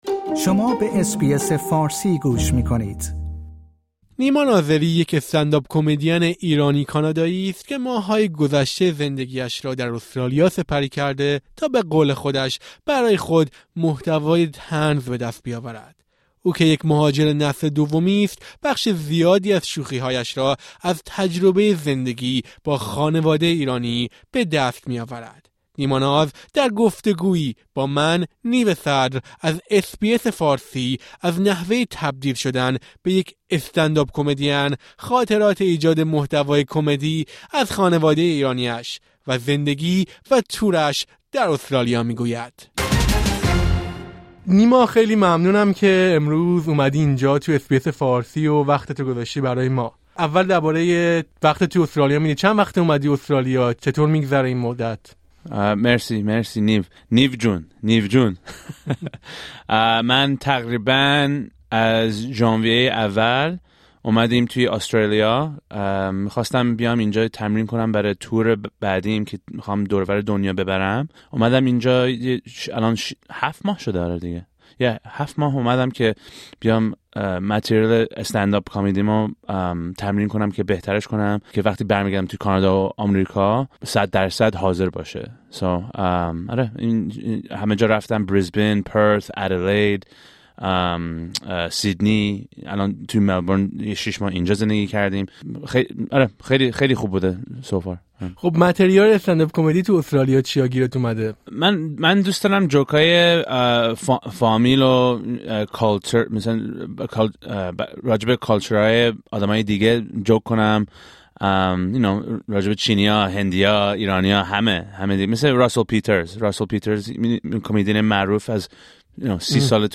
گفت‌وگویی با اس‌بی‌اس فارسی